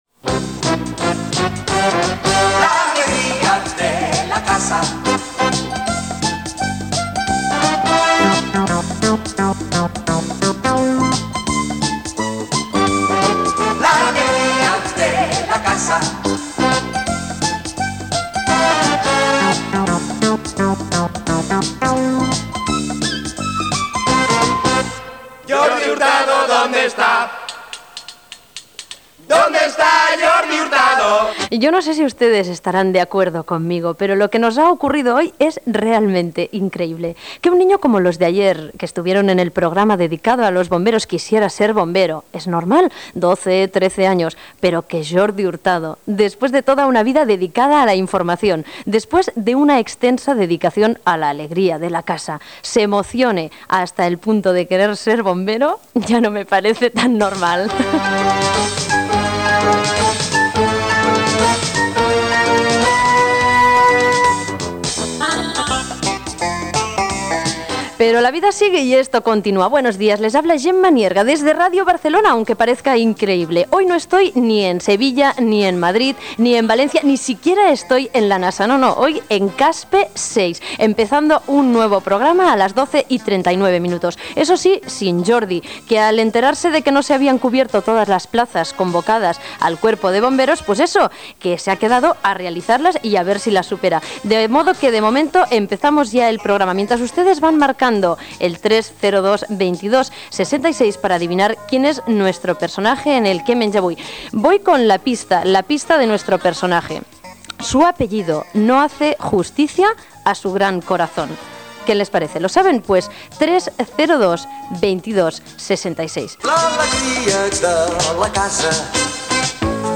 Careta del programa, presentació, telèfon de participació, indicatiu, secció "què menja avui?" amb trucades de l'audiència
Entreteniment